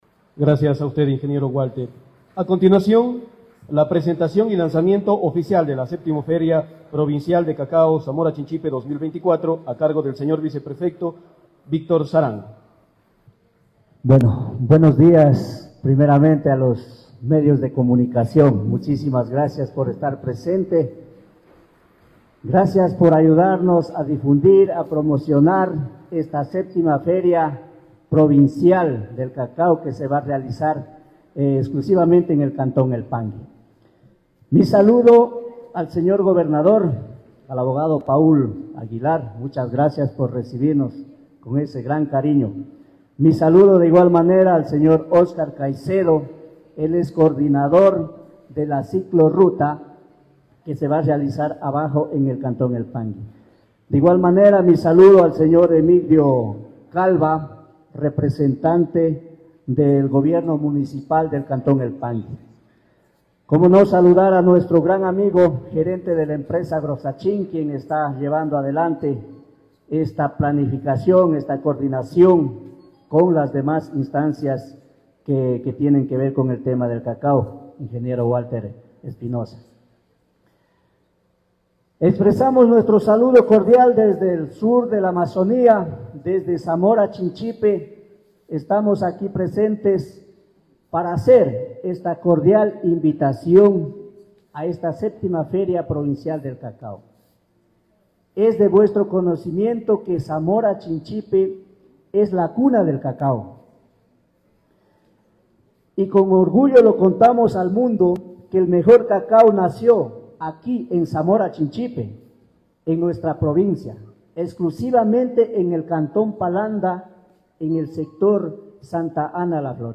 VÍCTOR SARANGO, VICEPREFECTO